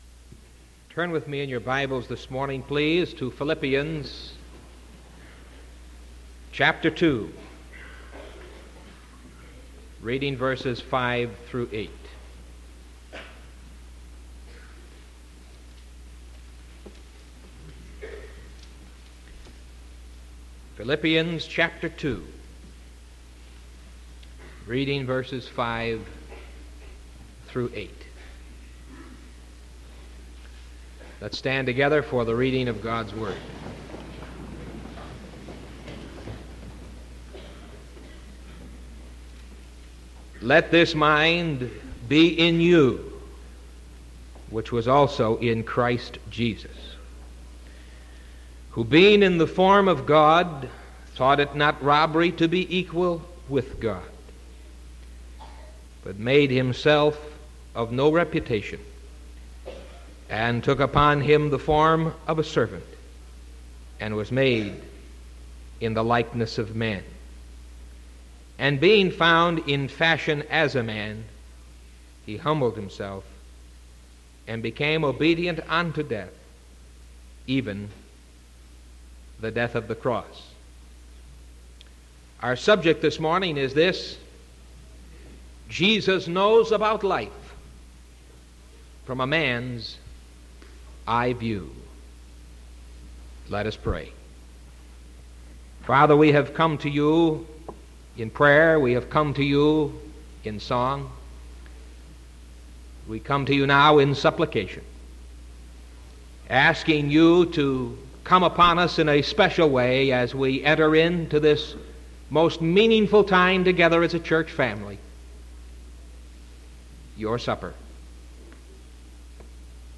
Sermon October 6th 1974 AM